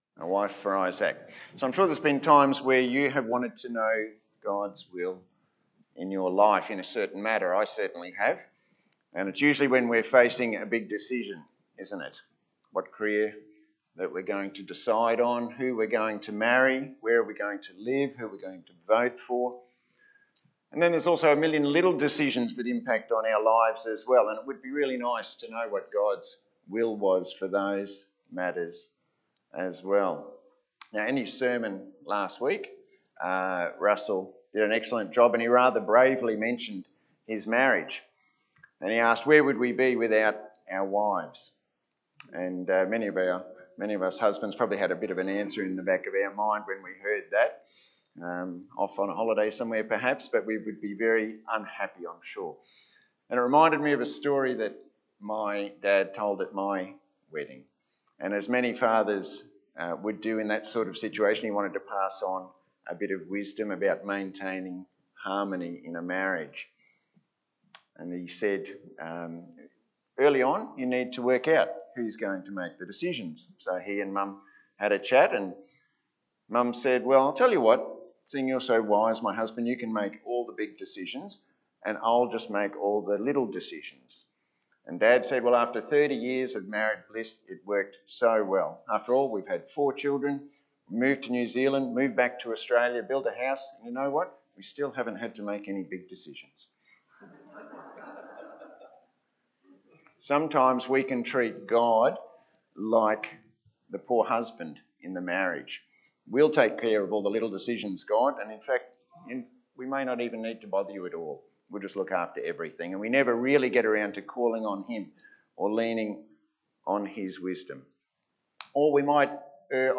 Passage: Genesis 24 Service Type: Sunday Morning